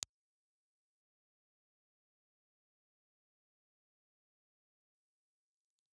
Implulse response file for a small Tannoy cardioid ribbon microphone.
Tannoy_Small_UnID__IR.wav
Small directional ribbon microphone from Tannoy.